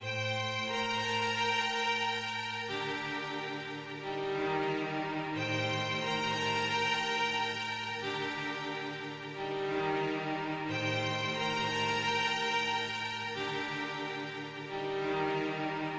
描述：电子串环（120 bpm）
Tag: 电子 合成器 背景 字符串 SY